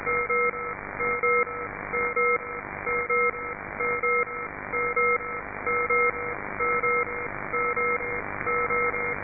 А не кто не в курсе, что за маяки работают в районе 1.815 мгц? передается просто "пик" иногда накладывается по два или три "пика"